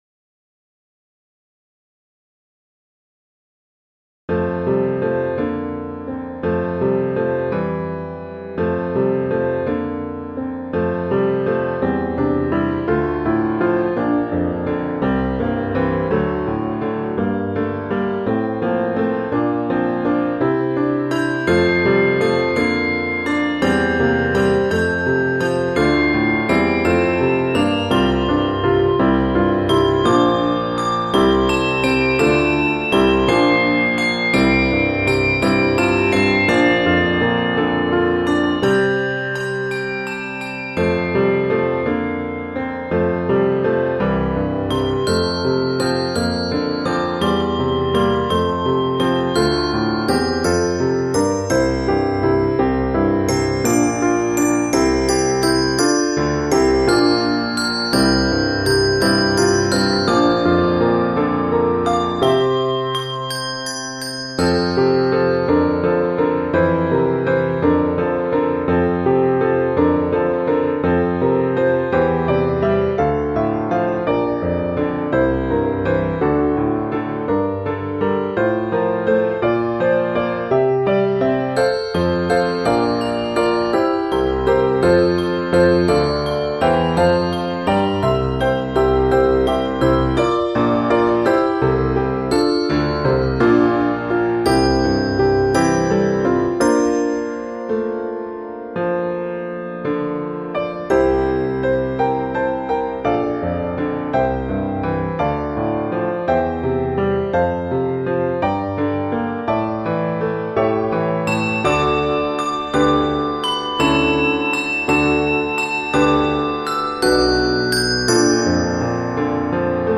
Digital Product, MP3 Accompaniment